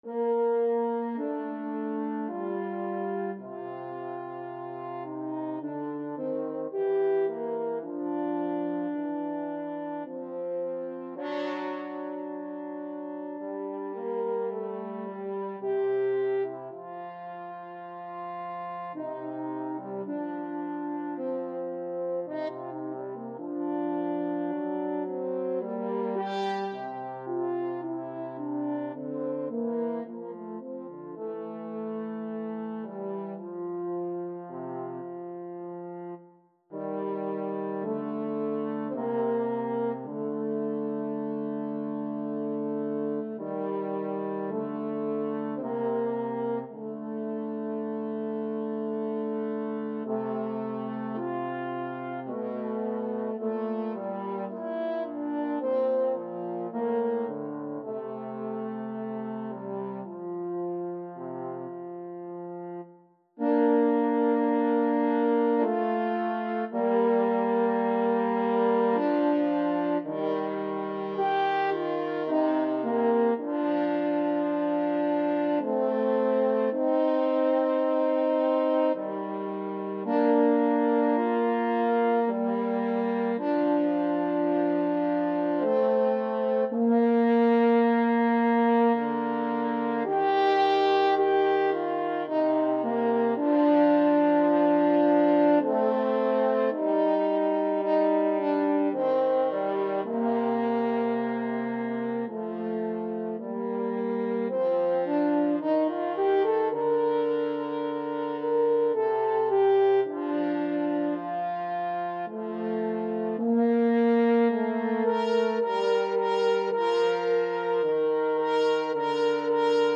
Free Sheet music for French Horn Duet
Bb major (Sounding Pitch) F major (French Horn in F) (View more Bb major Music for French Horn Duet )
3/4 (View more 3/4 Music)
Andante sostenuto ( = 54)
Classical (View more Classical French Horn Duet Music)